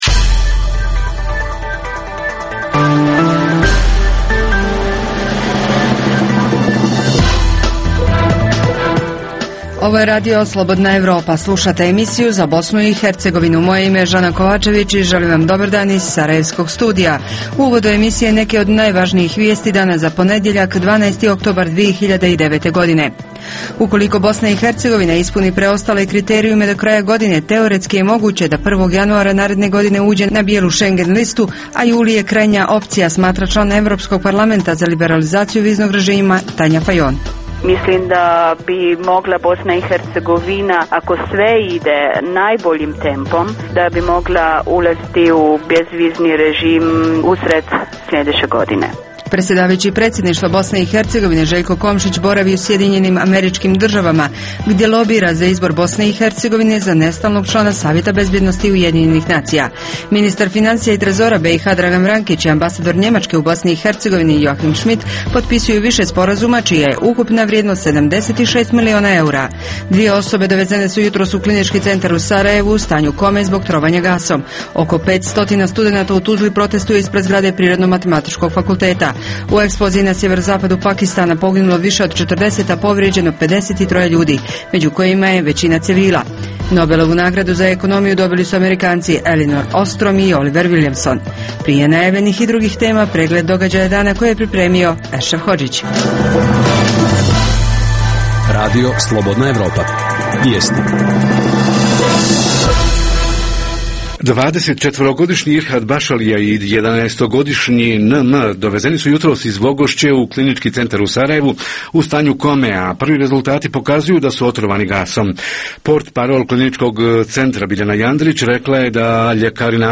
- Objavljujemo intervju sa Tanjom Falon koja je u izvještaju Evropskog parlamenta za viznu liberalizaciju zatražila je da se za građane BiH i Albanije ukinu vize za ulazak u EU, čim ove dvije zemlje ispune tražene uslove.